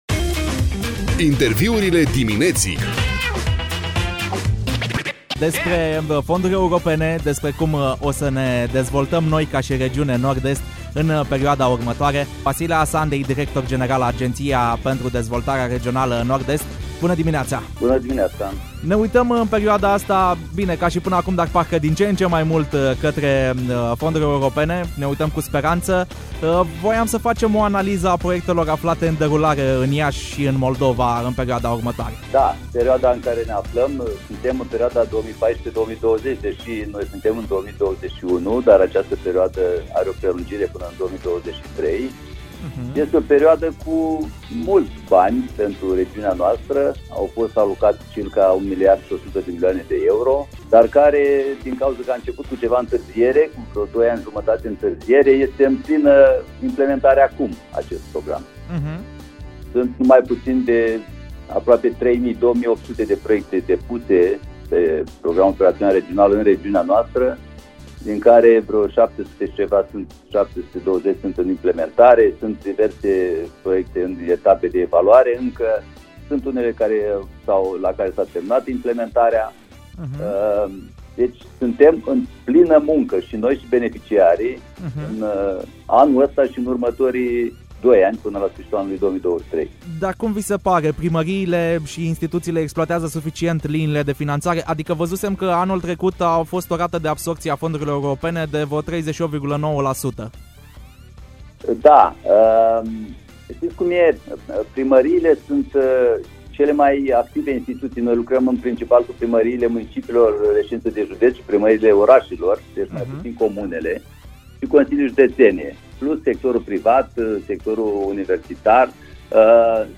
Integral Interviul